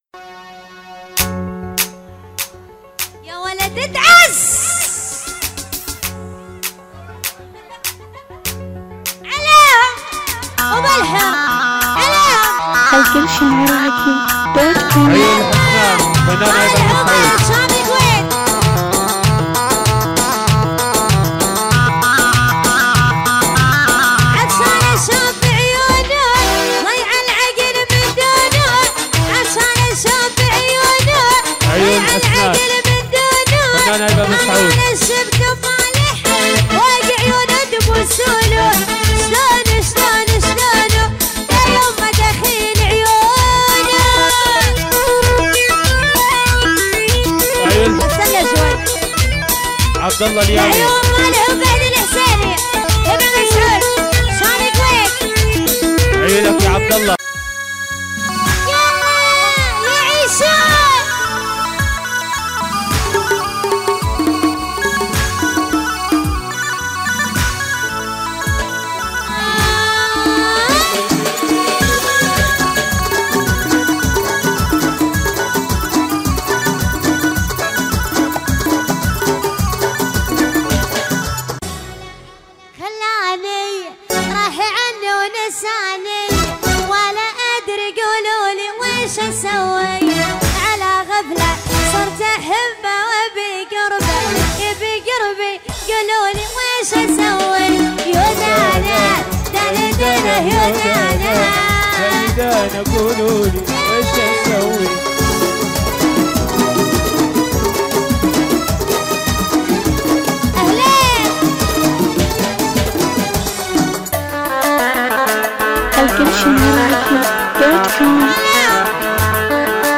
دبكات حصريه